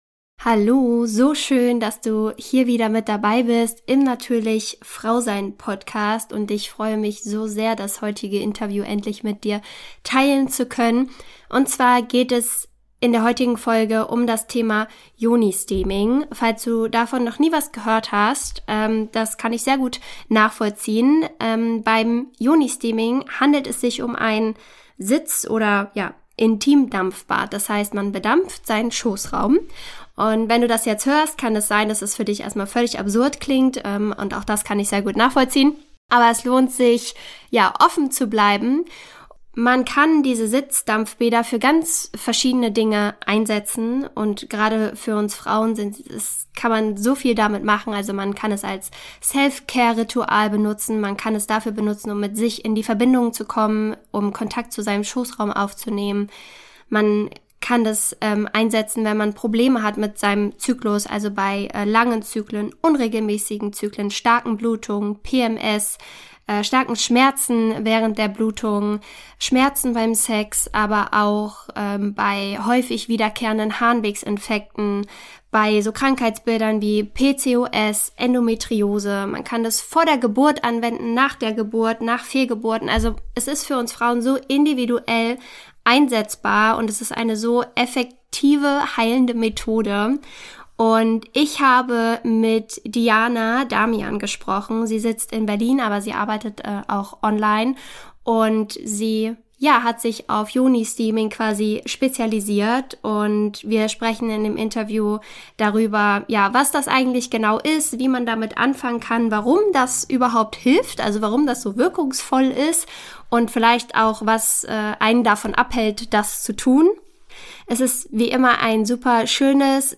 Am Ende des Interviews erhältst du eine Anleitung, für deine ersten Schritte mit Yoni Steaming und was du unbedingt beachten und vermeiden solltest.